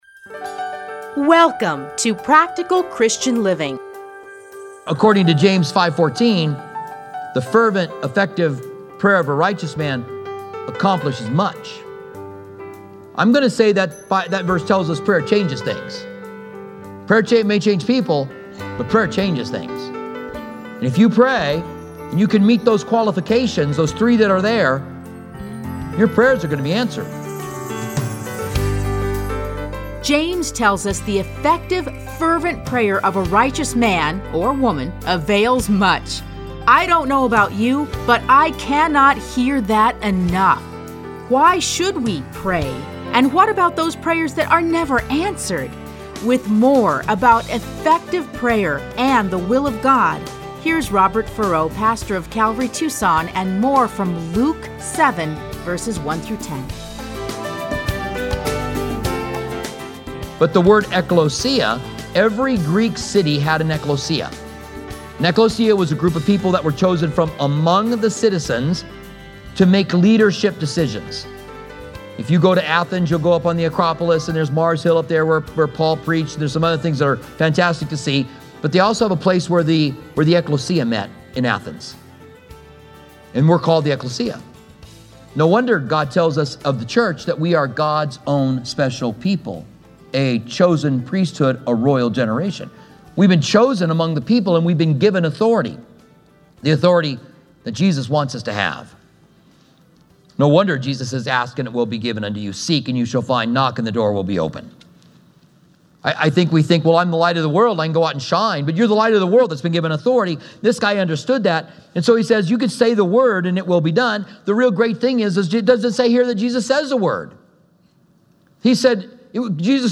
Listen to a teaching from Luke 7:1-10.